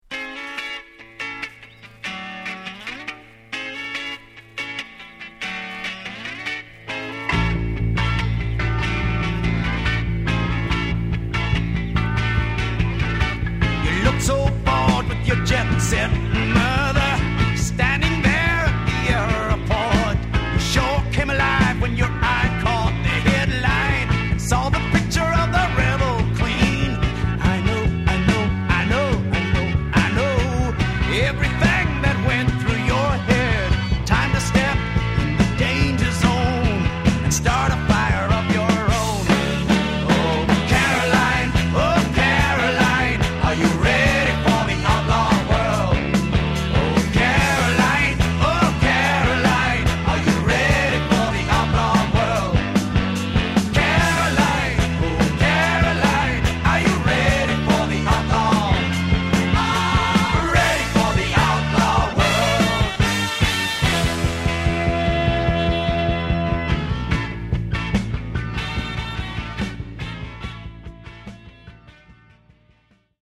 Genre: Power Pop